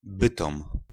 Bytom (Polish pronunciation: [ˈbɨtɔm]